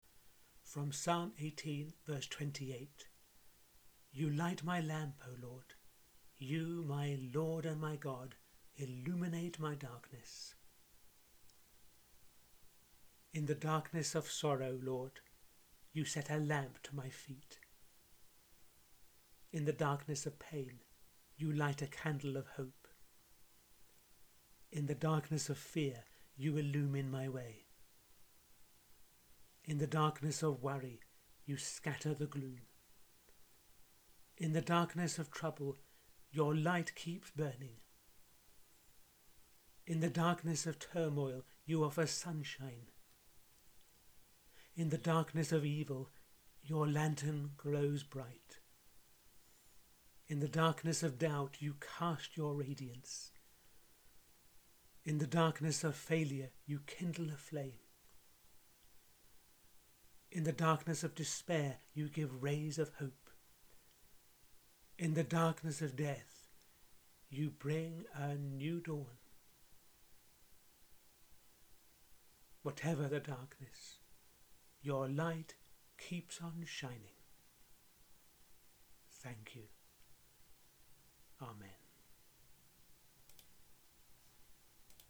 The fifth prayer in the audio series I’m running over these few weeks: